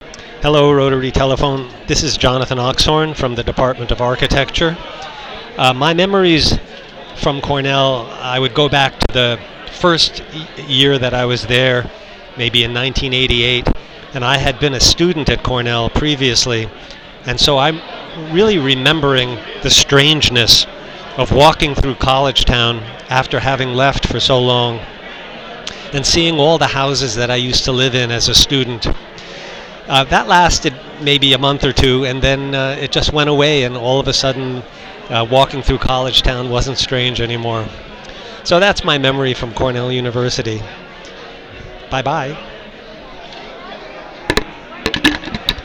At the annual retirement reception in May, retired faculty have the opportunity to record a memory from their time at Cornell.
Retired faculty member recording a memory from their time at Cornell.